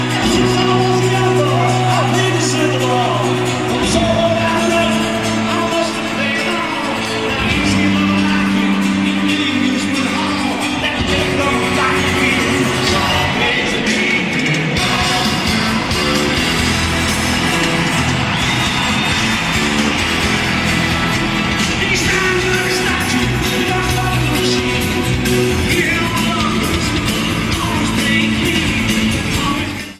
Comments: Poor audience recording.
Sound Samples (Compression Added):